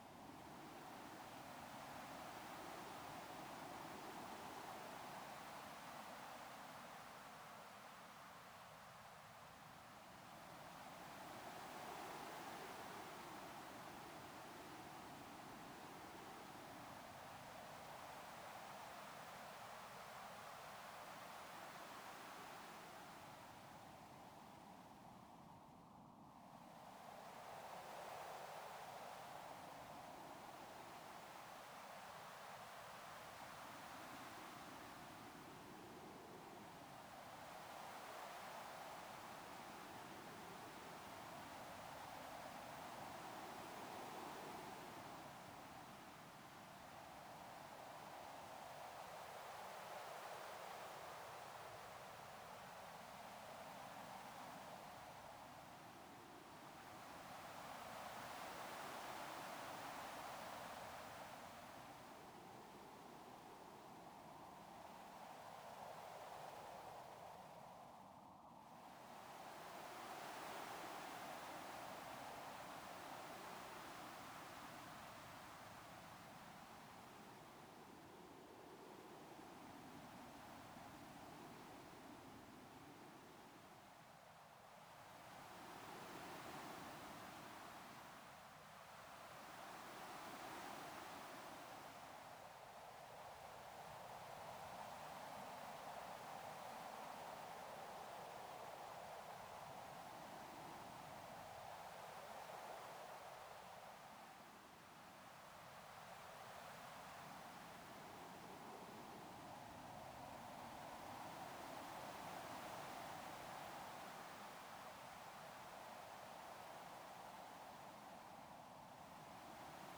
ajout de la piste de vent séparée
vent.ogg